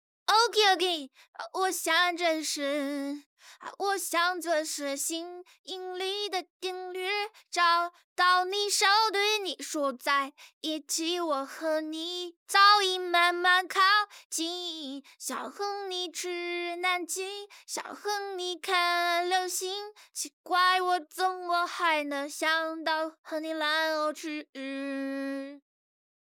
林川 少年/幼态/正太音少年感 RVC模型
温御推理后
唱歌推理表现